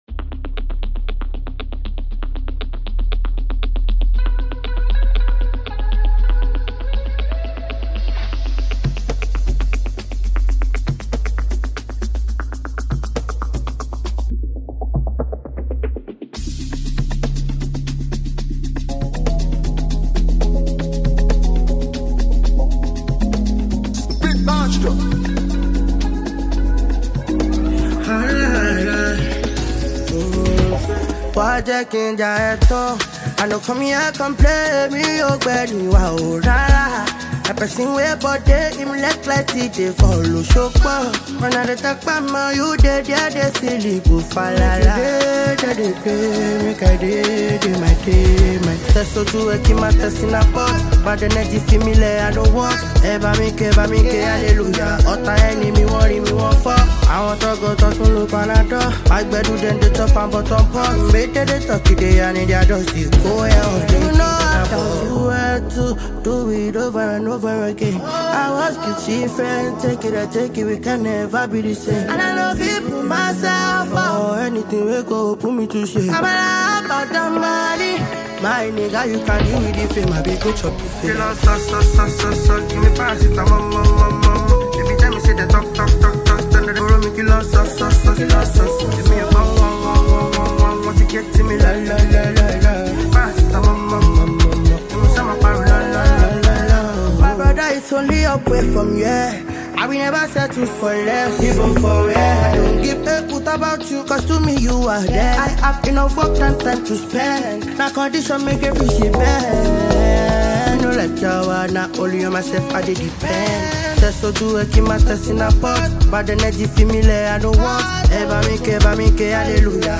Afrobeats
Afrobeat with contemporary influences